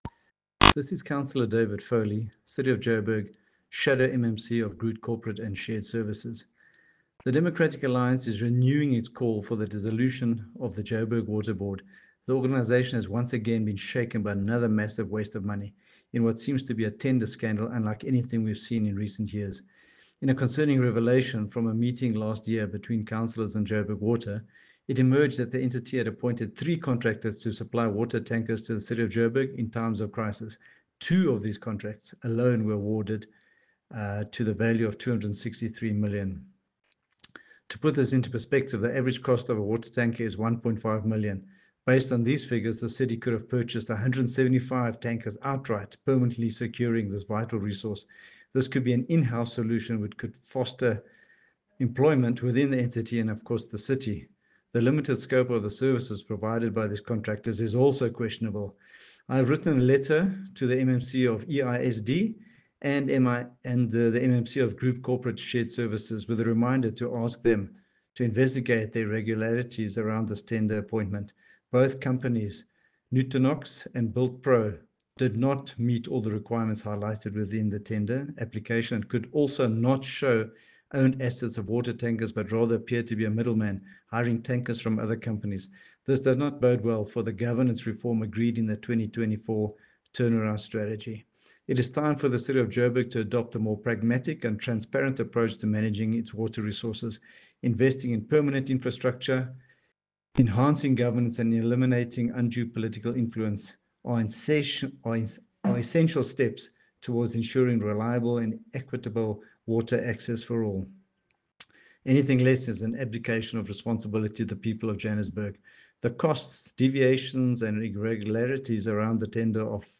Note to Editors: Please find an English soundbite by Cllr David Foley
David-Foley_ENG_Renewed-Call-for-board-of-Joburg-Water-dissolution.mp3